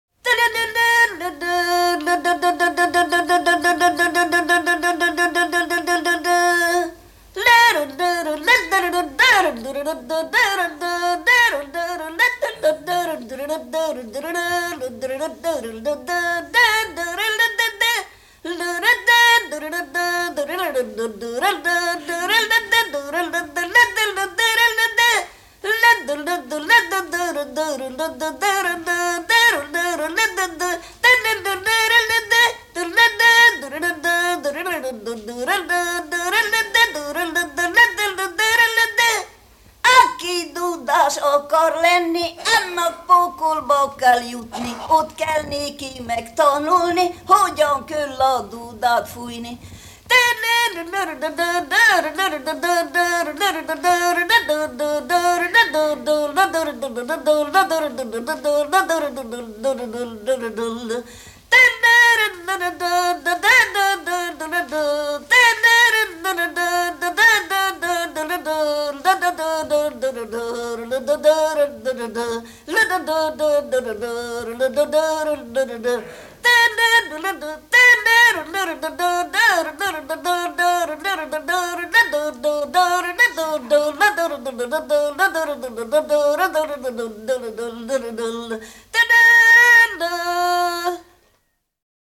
Kezdősor "Dudautánzás"
Műfaj Hangszeres
Részl.műfaj Hangszerutánzás
Helység Szany